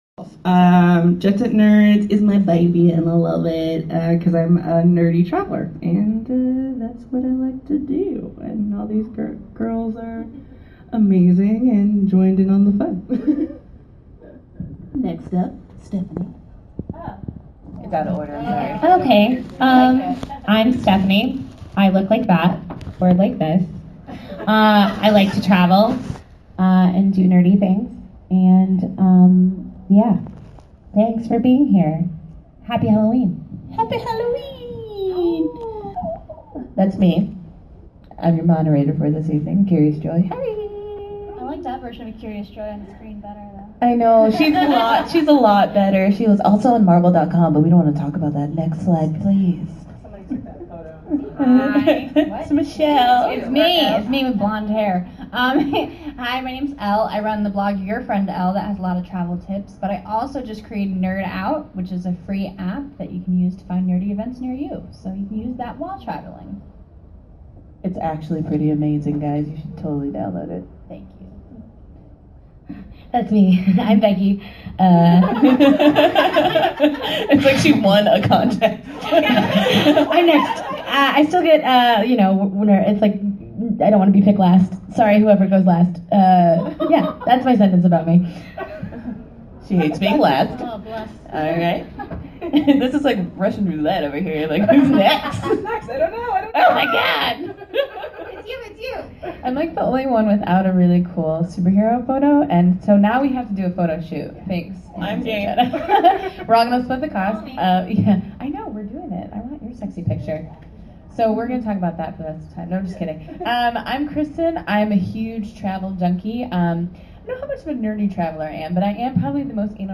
One of the Saturday panel’s at Comikaze I attended was Jet Set Nerds. These group of traveling girls talk about visiting nerdy destinations all over the world and give out helpful travel tips.
The full panel is below, but please note there is adult language so viewer discretion is advised (it’s really not that bad at all).